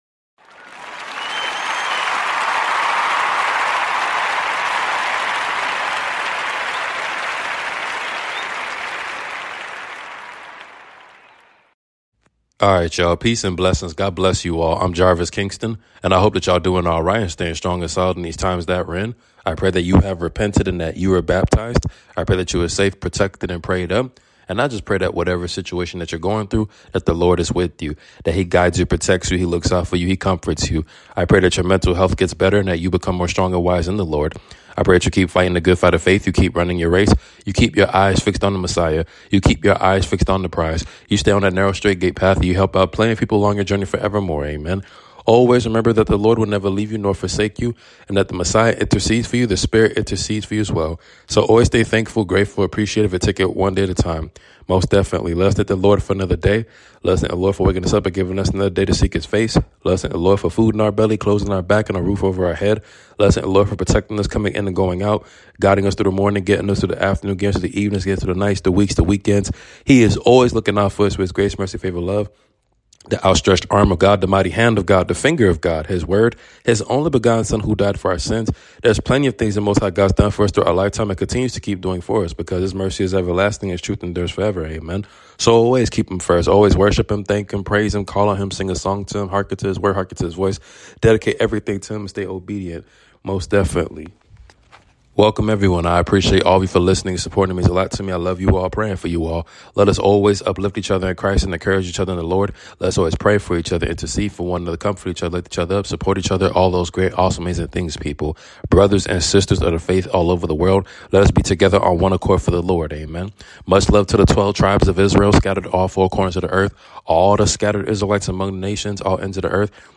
Your Nightly Prayer 🙏🏾 Colossians 3:23-24